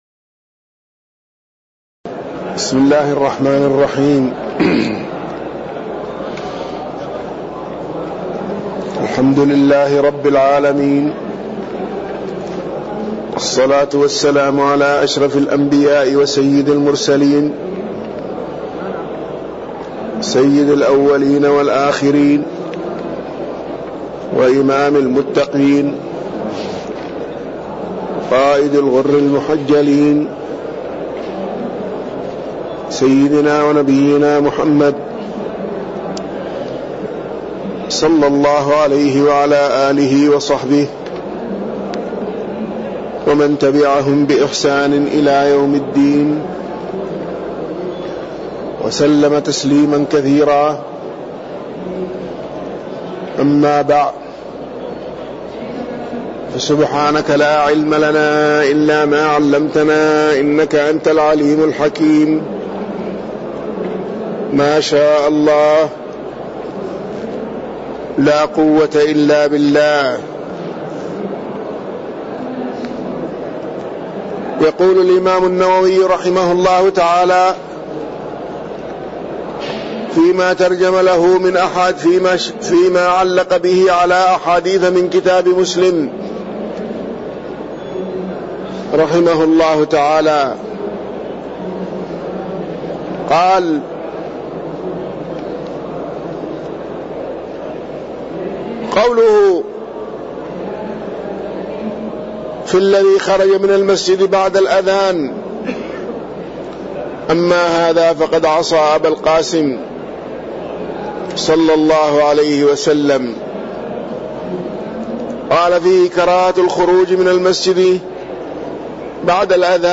تاريخ النشر ١١ ربيع الأول ١٤٣٠ هـ المكان: المسجد النبوي الشيخ